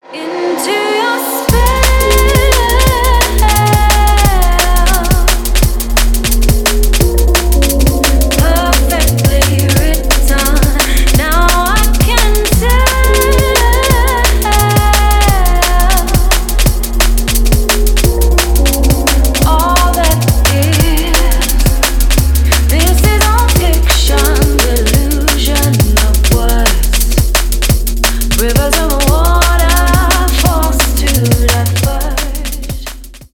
• Качество: 320, Stereo
Electronic
красивый женский голос
Liquid DnB
Стиль: Liquid drum&bass